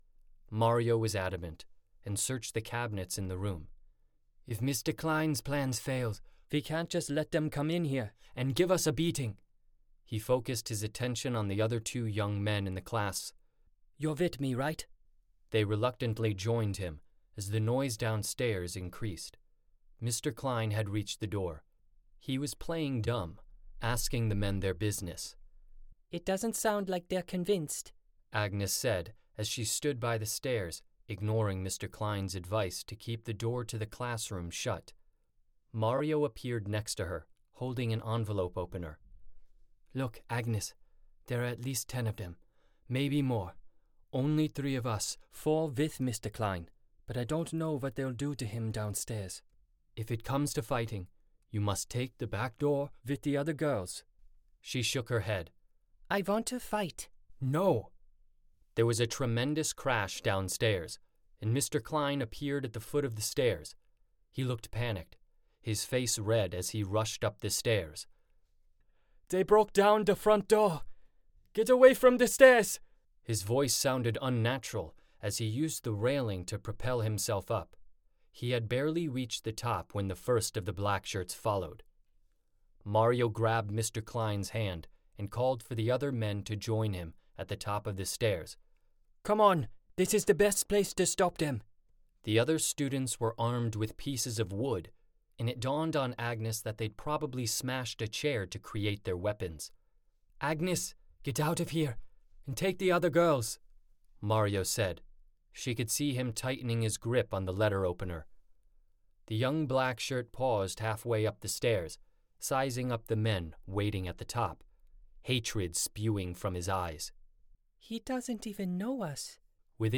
Tracks to Freedom, Audiobook